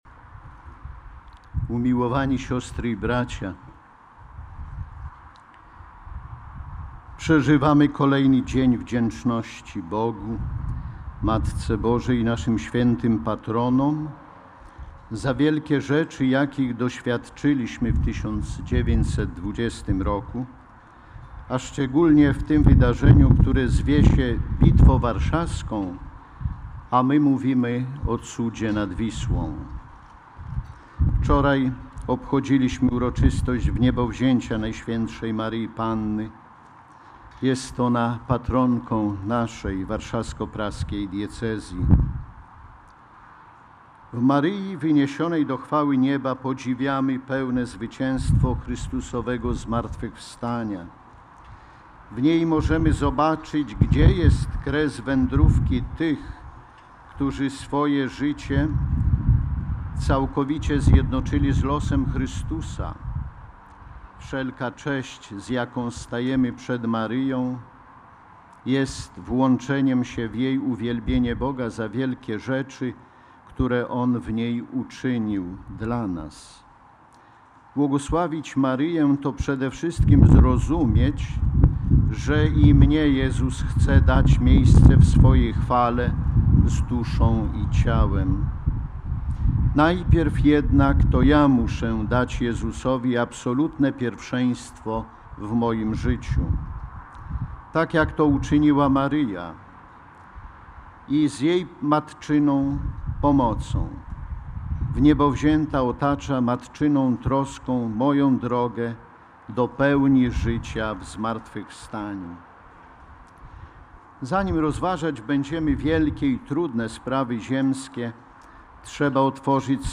Biskup Warszawsko-Praski przewodniczył Mszy Św. w Sanktuarium św. Jana Pawła II w Radzyminie.
Uroczystość była punktem centralnym obchodów 100-lecia Bitwy Warszawskiej. W homilii Ordynariusz Warszawsko-Praski mówił, że zwycięstwo 1920 r. poprzedziły publiczne adoracje i procesje błagalne.
homilia-cała.mp3